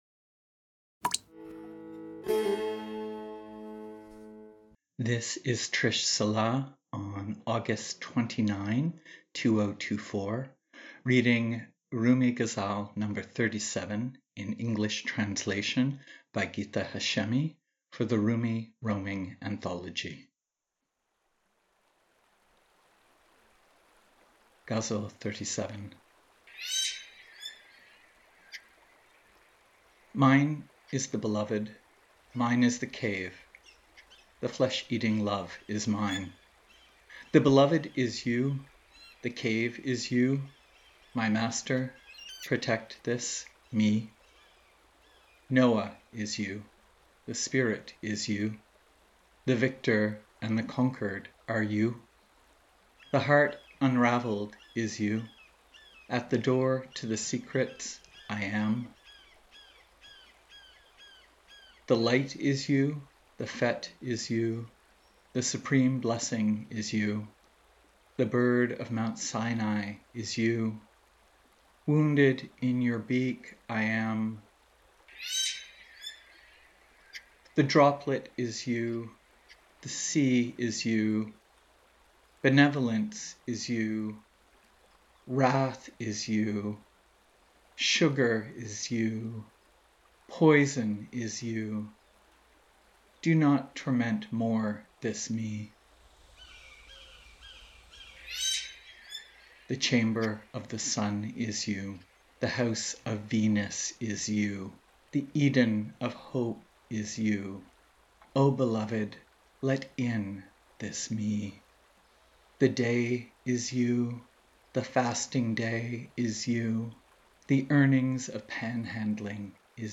Rumi, Ghazal 37, Translation, Rumi roaming, Poetry